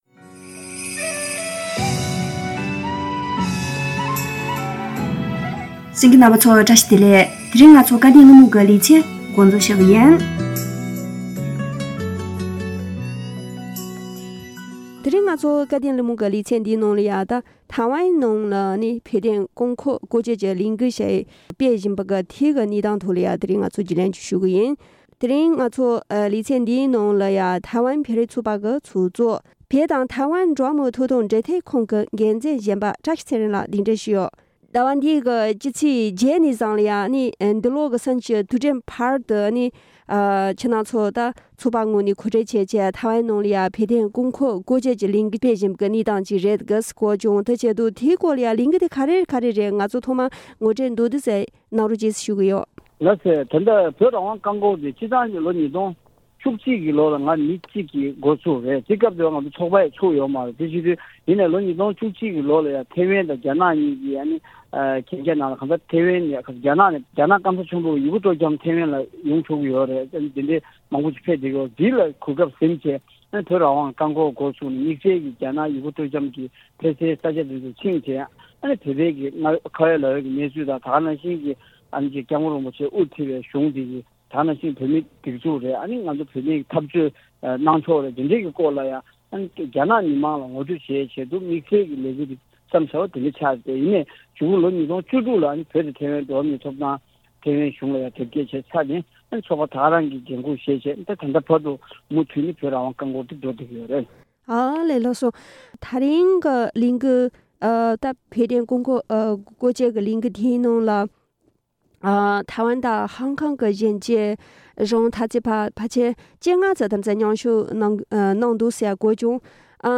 སྐབས་དོན་གླེང་མོལ་གྱི་ལེ་ཚན་ནང་།